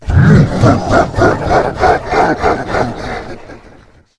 spawners_mobs_balrog_neutral.1.ogg